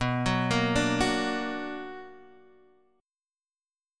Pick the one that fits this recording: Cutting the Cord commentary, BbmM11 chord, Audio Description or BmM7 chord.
BmM7 chord